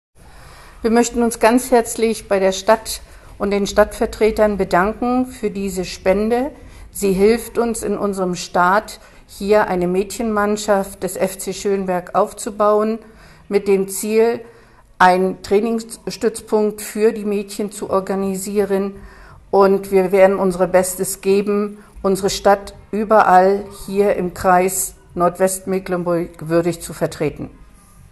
Stimmen